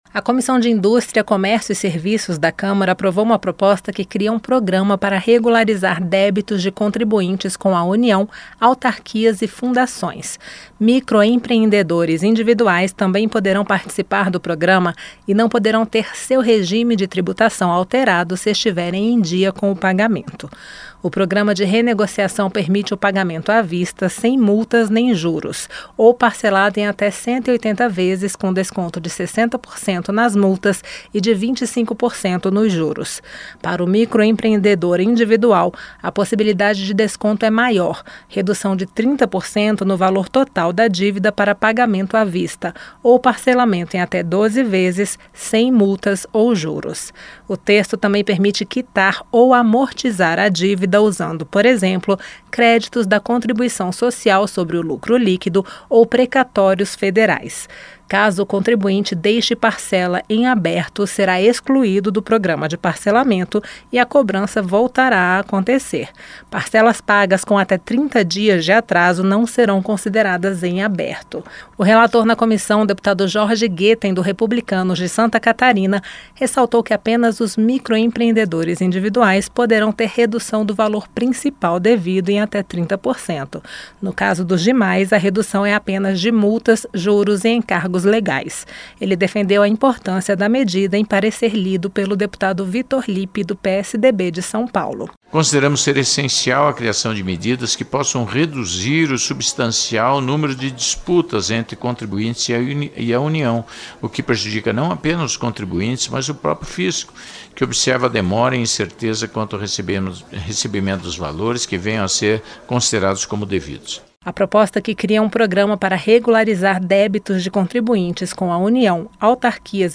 COMISSÃO DA CÂMARA APROVA CRIAÇÃO DE PROGRAMA PARA REGULARIZAR DÉBITOS DE CONTRIBUINTES COM UNIÃO, AUTARQUIAS E FUNDAÇÕES. A REPORTAGEM